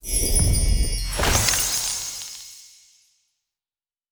chest_epic_open.wav